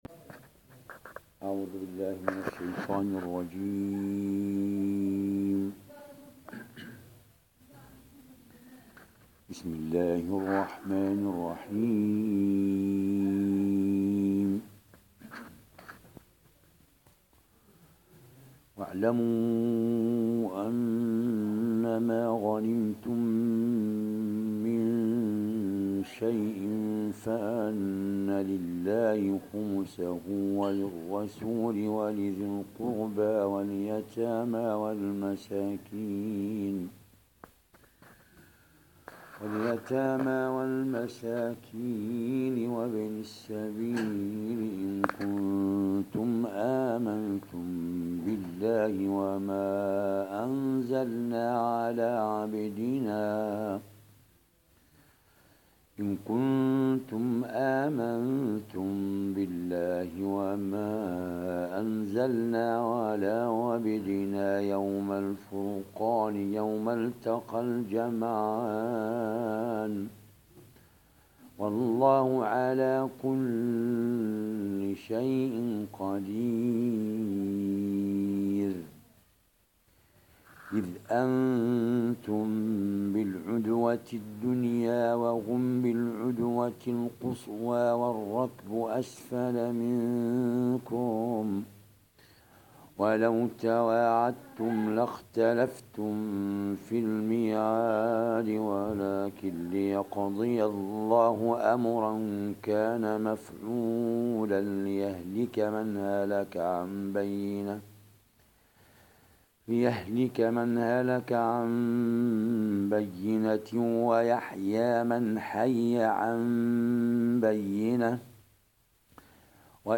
Besucher Rezitationen 1747 Hören 1 Gefällt mir Gefällt mir Teilen Herunterladen Andere Code einbetten Fehler melden ladet...